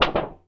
metal_tin_impacts_wobble_bend_05.wav